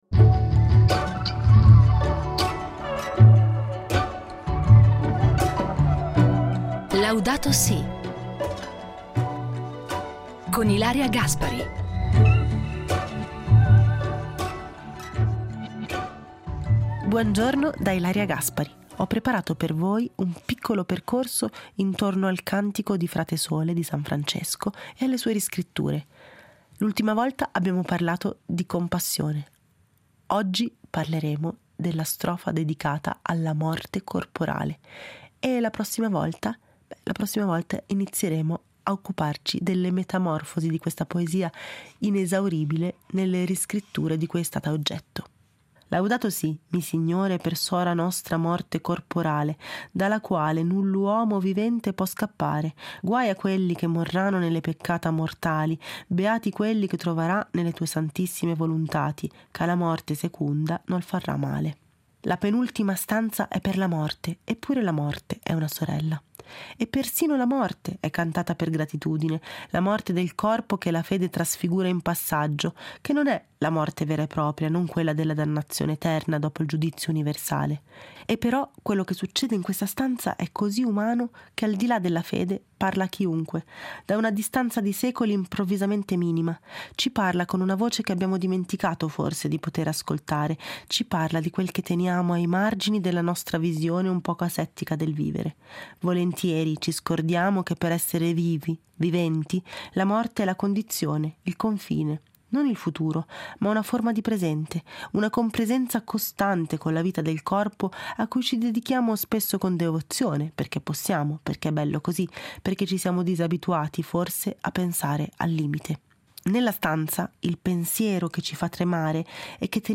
Il Cantico dei cantici letto da Ilaria Gaspari